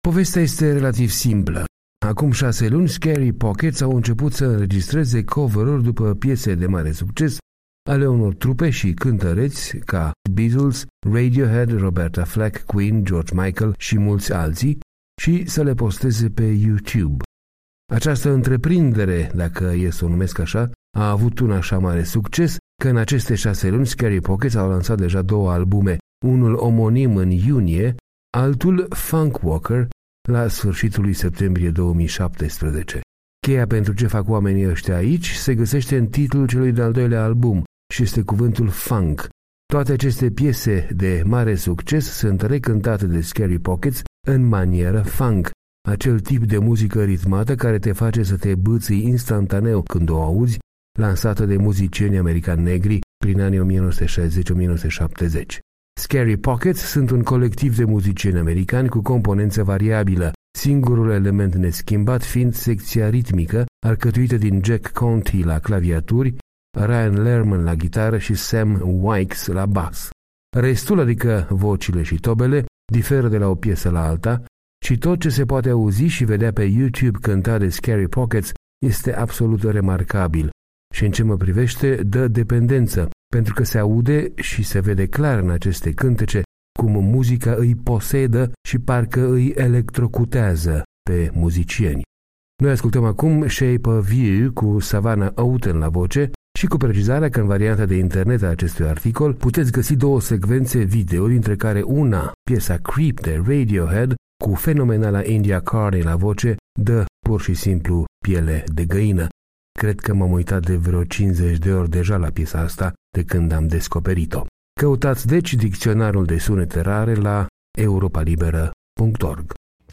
Toate aceste piese de mare succes sînt recântate de Scary Pockets în manieră funk, acel tip de muzică ritmată care te face să te bîțîi instantaneu când o auzi, lansată de muzicienii americani negri prin anii 1960-1970.